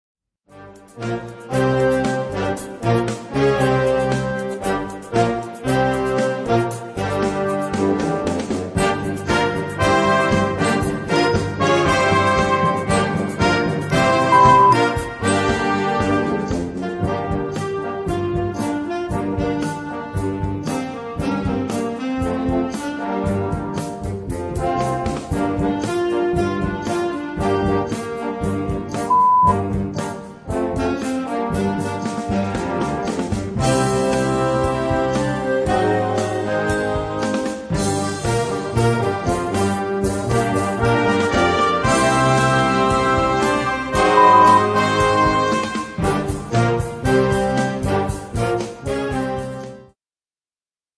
Gattung: Jugendmusik
Besetzung: Blasorchester